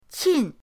qin4.mp3